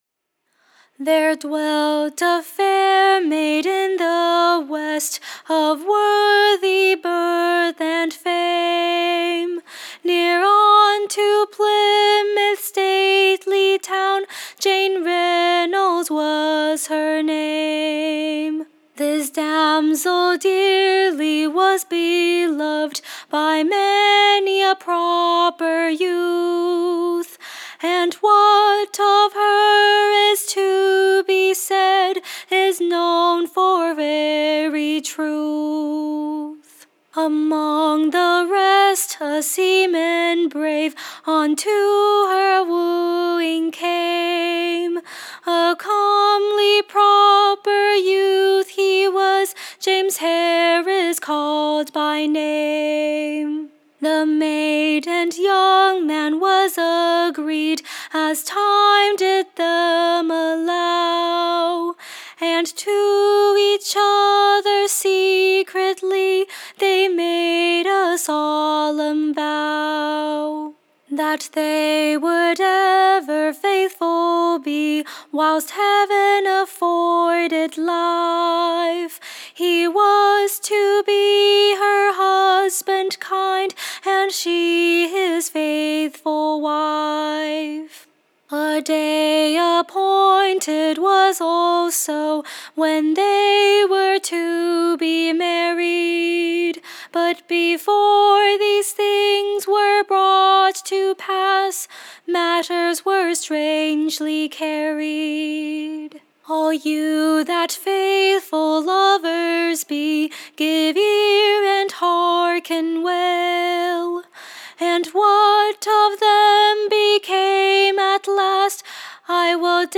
Recording Information Ballad Title A WARNING for Married VVomen.
Tune Imprint To a west-country Tune, call'd, The fair Maid of Bristol: or, John True.